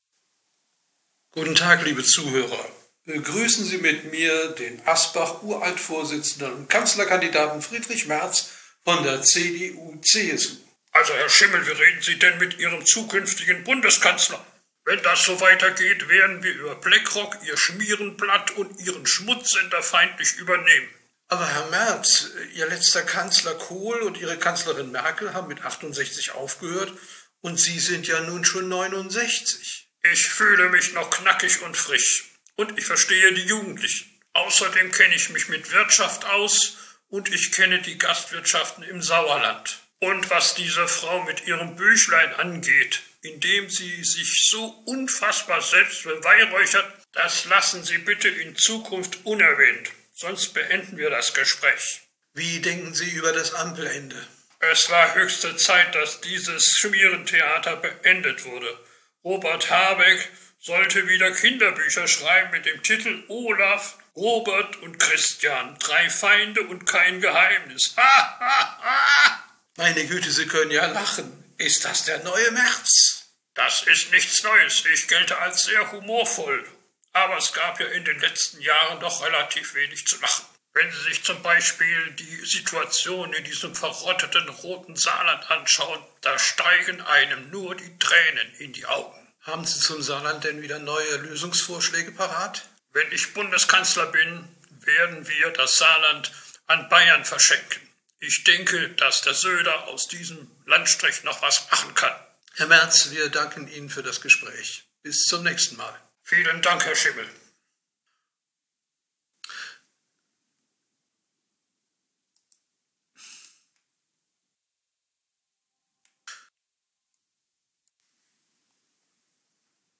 Merz Interview 8 -Alter